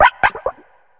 Cri de Nigirigon dans sa forme Courbée dans Pokémon HOME.
Cri_0978_Courbée_HOME.ogg